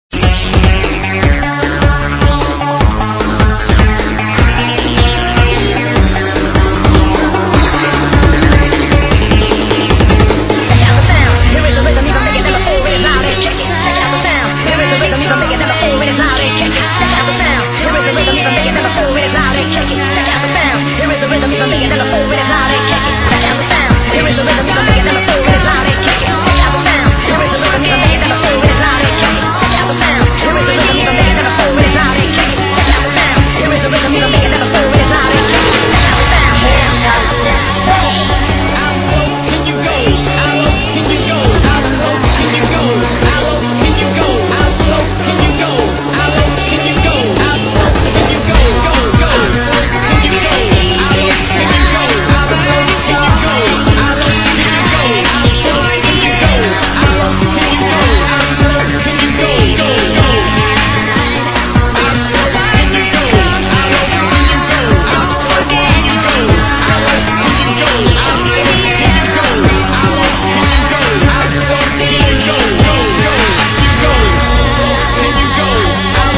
the song in the background with the female vocals is :
scouse house at its finest